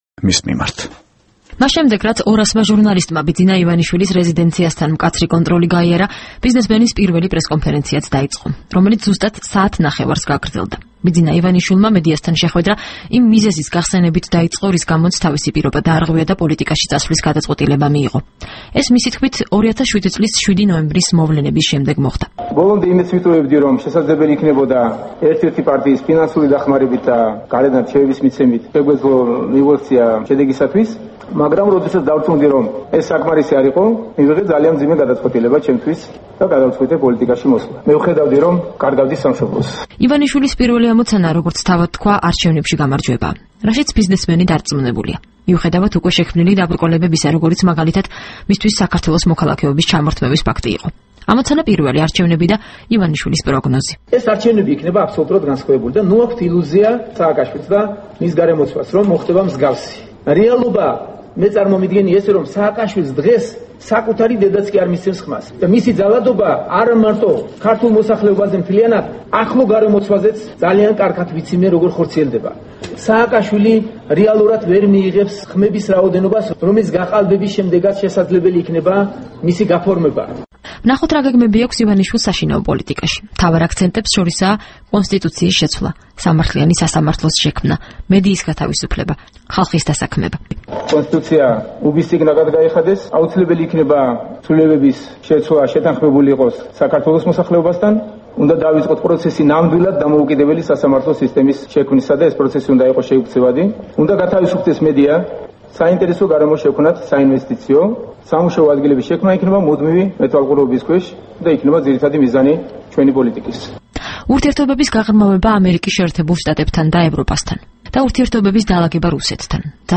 ბიძინა ივანიშვილის პრესკონფერენცია